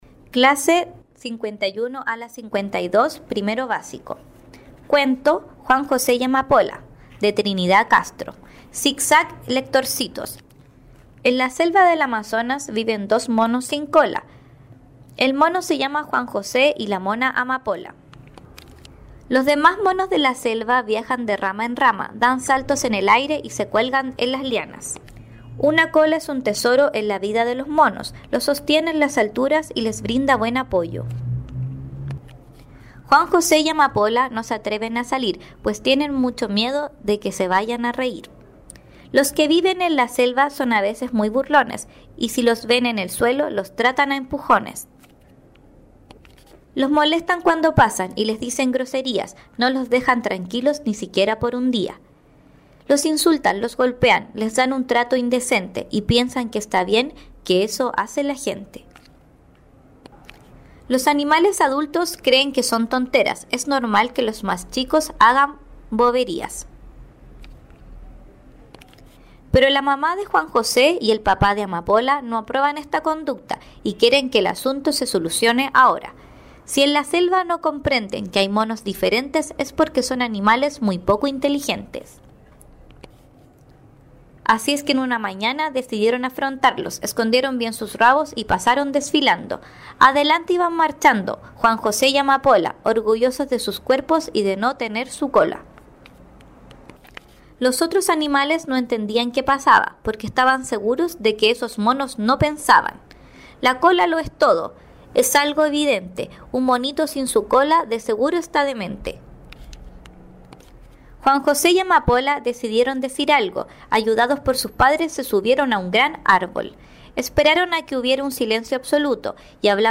Audiolibro: Juan José y Amapola
Tipo: Audiolibros